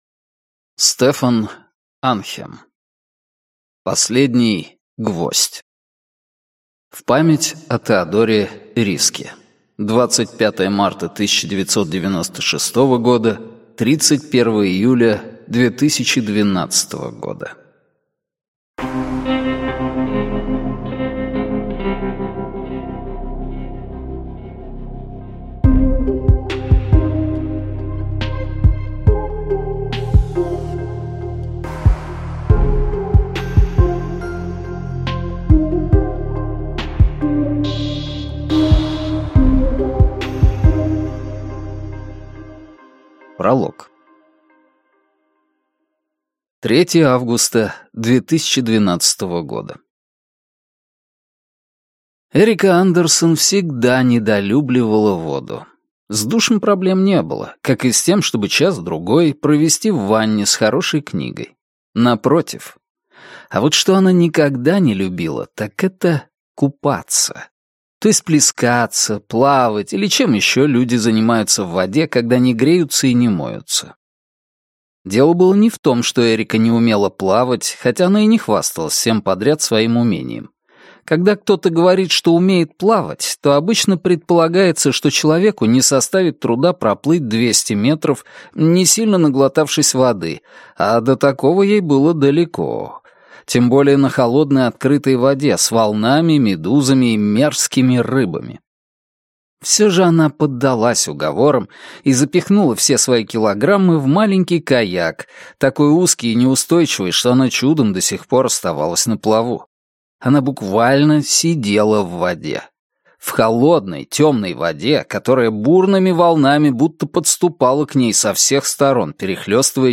Аудиокнига Последний гвоздь | Библиотека аудиокниг